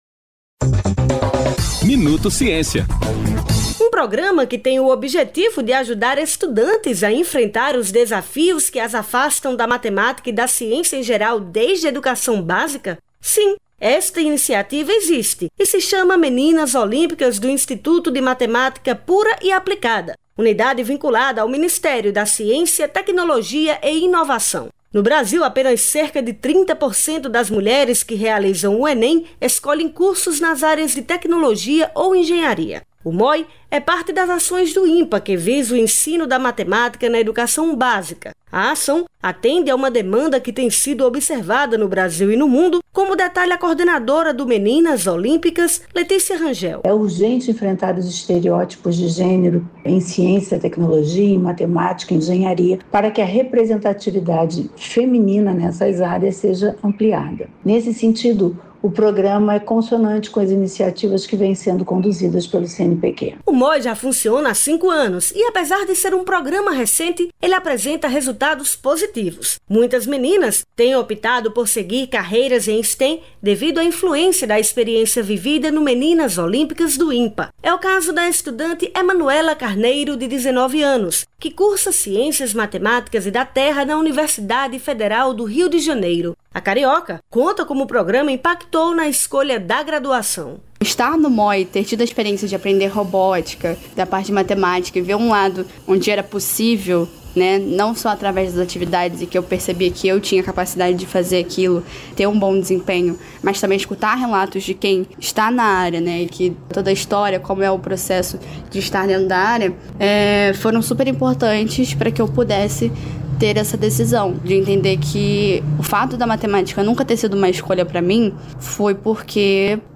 Boletim produzido pelo Ministério da Ciência, Tecnologia e Inovação (MCTI), com as principais informações do setor.